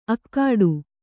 ♪ akkāḍu